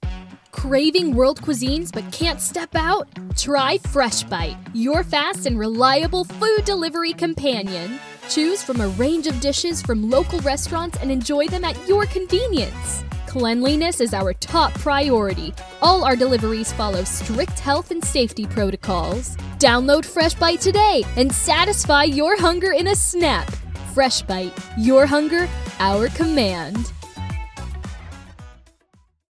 Demos
FreshBite Delivery, Upbeat:Spunky:Excited
Southern (American)
My timbre is youthful and bright with a clear and direct expression.